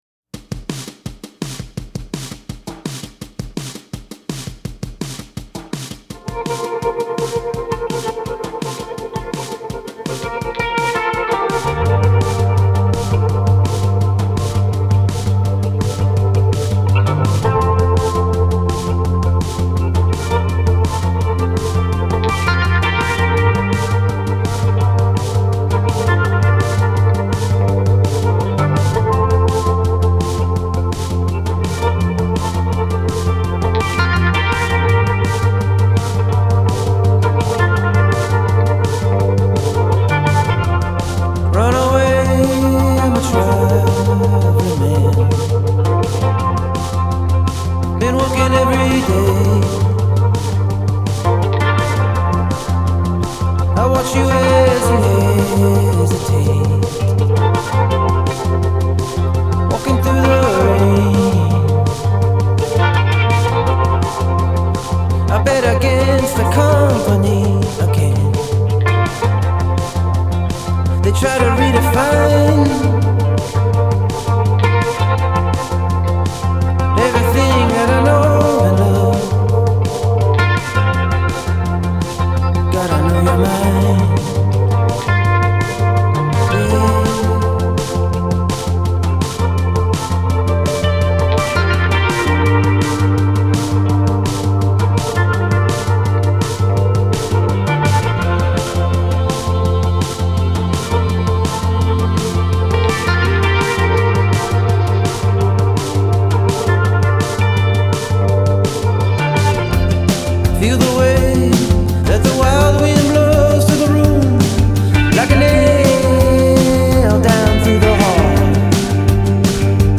dreamy indie rock